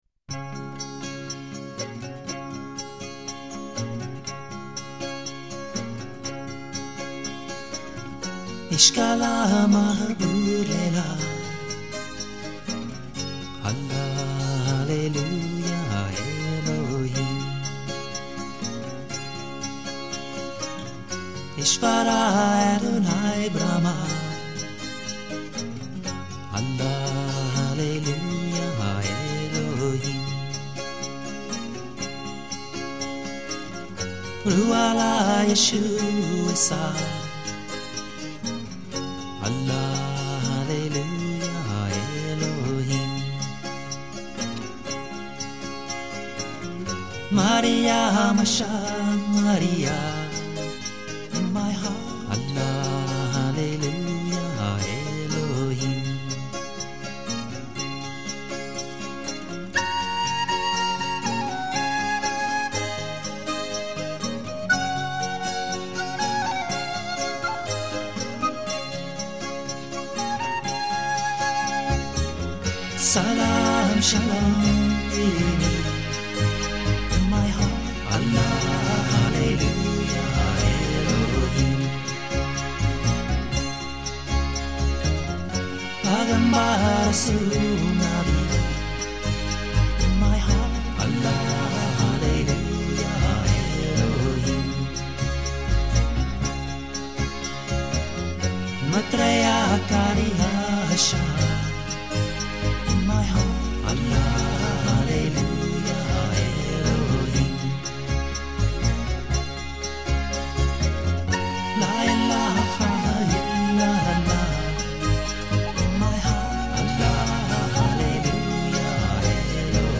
音乐类型: New Age